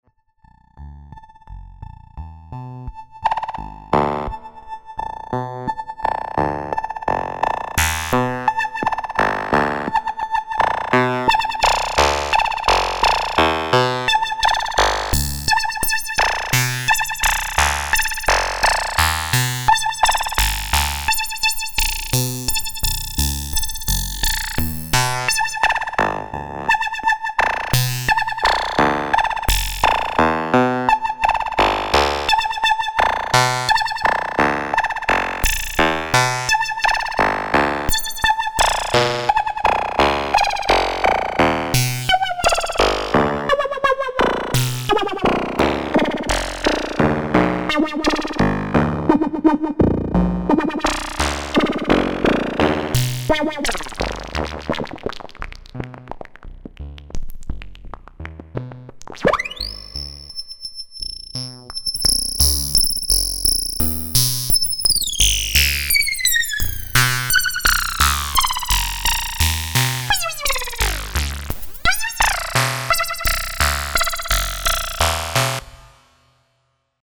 This board is for the Yamaha GX1 bandpass filter/resonator as used in the GX1 voice.
This includes a variable Q control, 1V/Octave CV response, and an FM input with reversing panel control.
Audio example courtesy of Robert Rich.